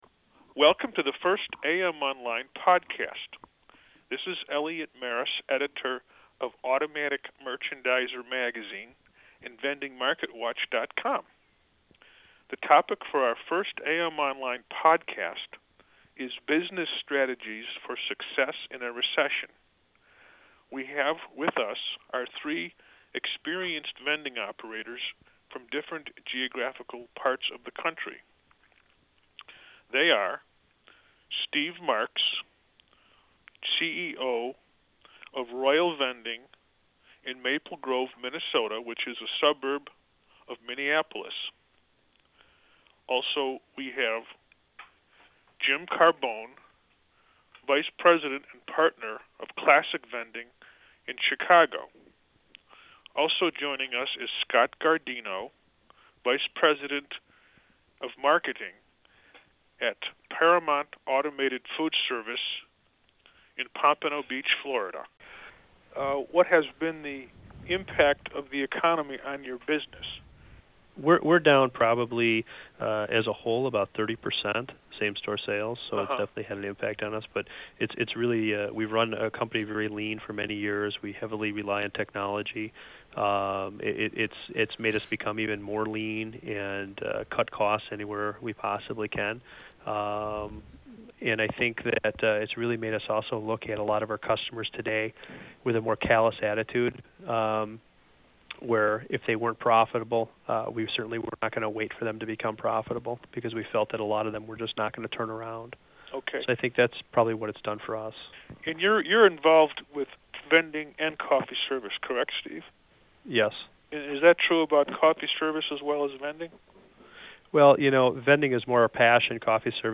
Three experienced vending operators in different geographic markets share their ideas about how to succeed during a recession. Operators must review their route schedules and scrutinize the return on investment for their accounts more frequently.